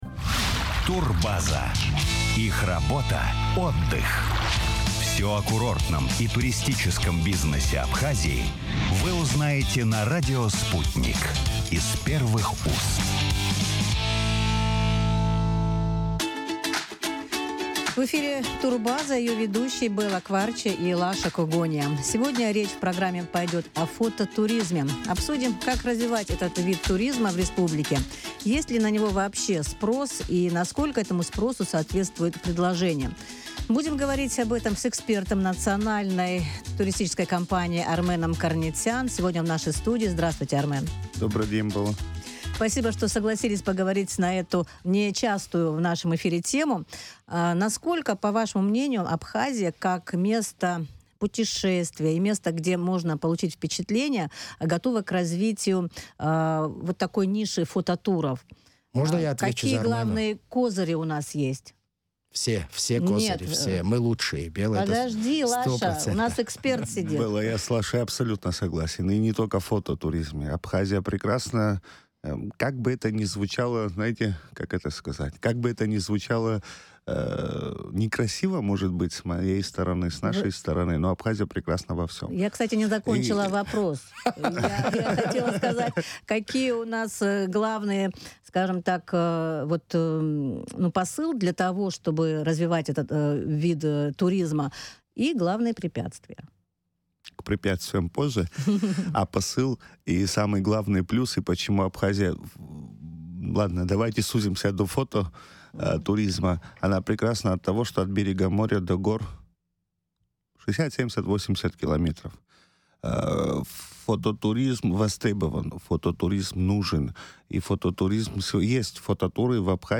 Чем привлекательна Абхазия для фотографов? О развитии этого направления туризма, в эфире радио Sputnik говорили с экспертом.